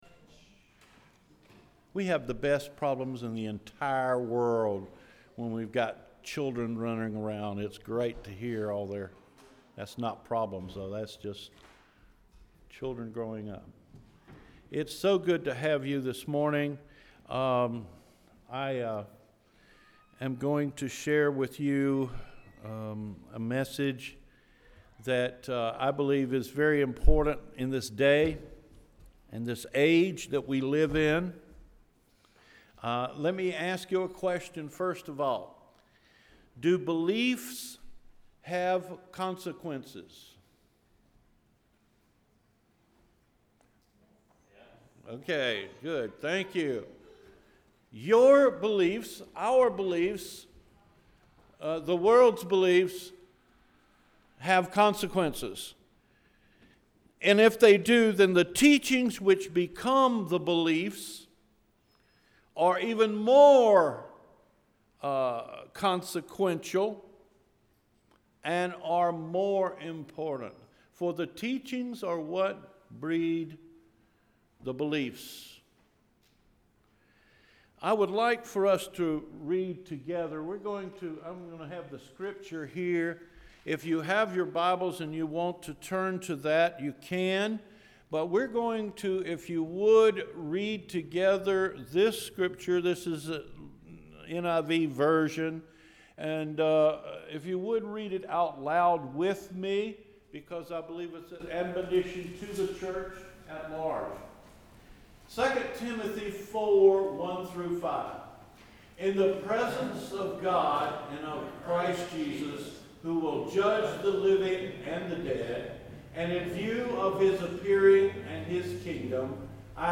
CedarForkSermon-7-30-17.mp3